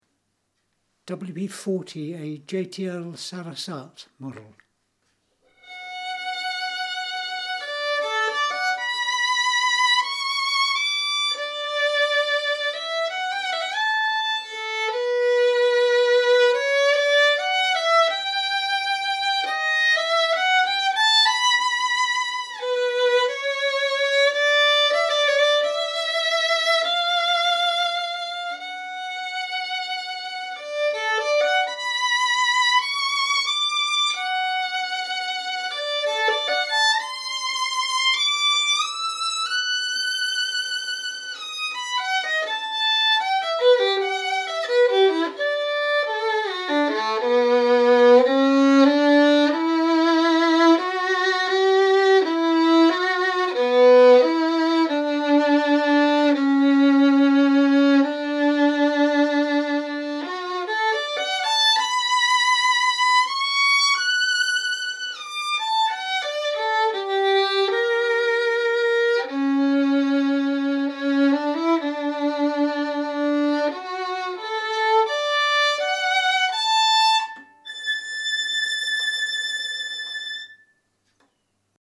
A French violin Sarasate model, made in 1925.